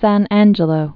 (săn ănjə-lō)